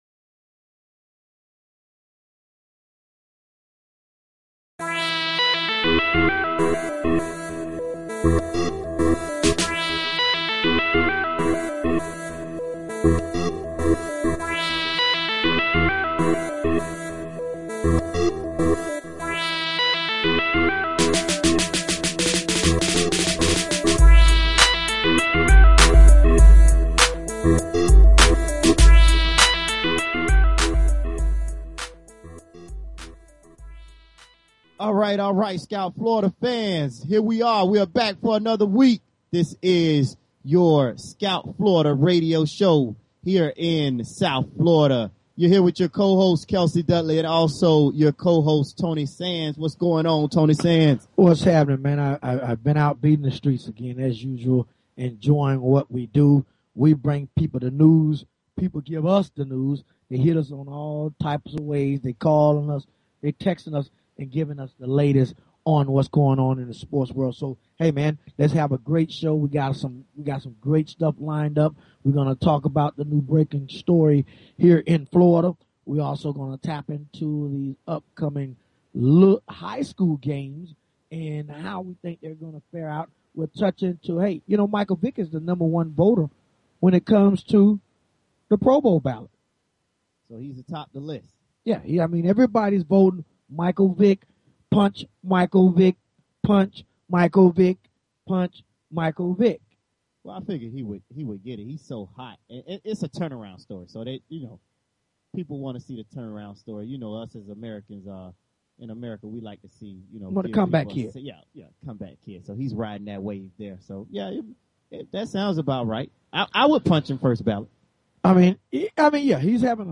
Talk Show Episode, Audio Podcast, Scout_Florida and Courtesy of BBS Radio on , show guests , about , categorized as